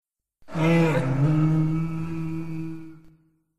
pain grunt 1
pain-grunt-1.mp3